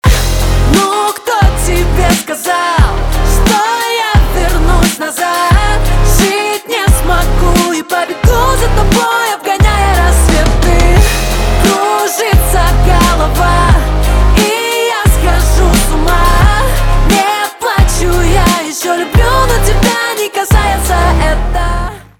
поп
битовые , басы , барабаны
гитара